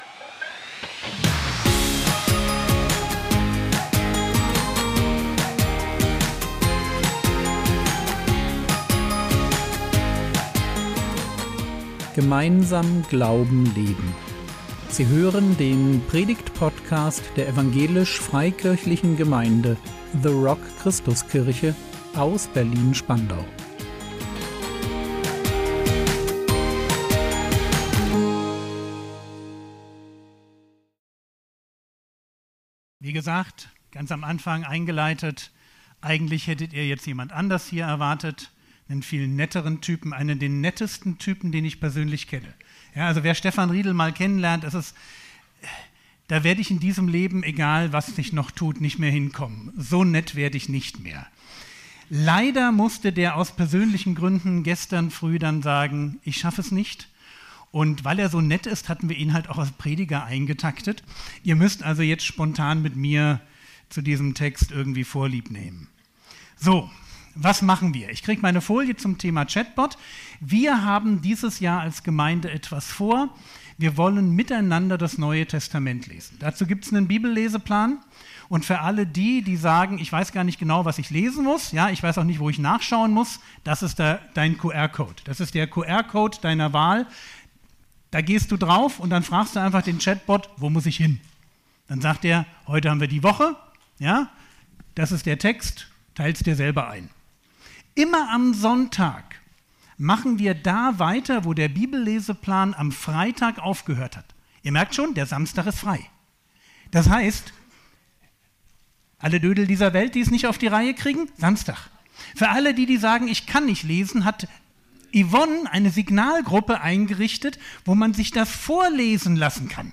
4 Punkte von Jesu Regierungserklärung | 11.01.2026 ~ Predigt Podcast der EFG The Rock Christuskirche Berlin Podcast
Impuls